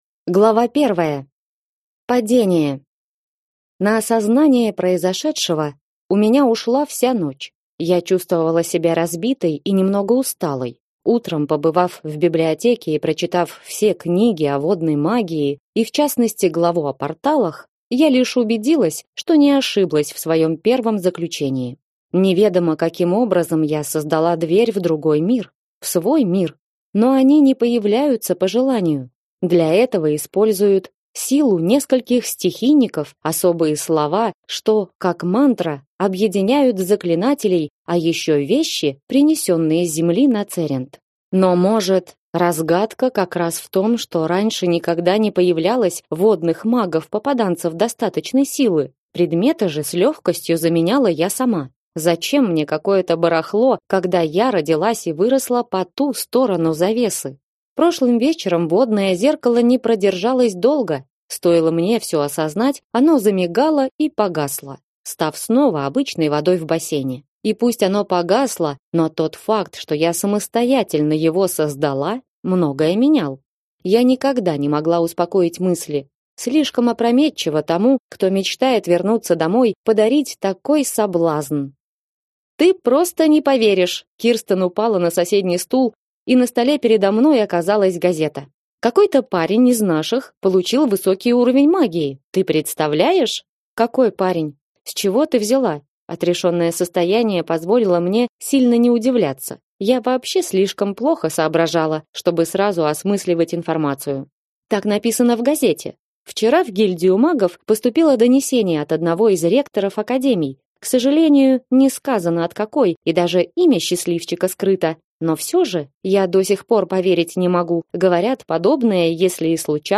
Аудиокнига Академия магии. Притяжение воды и пламени. Книга 2 | Библиотека аудиокниг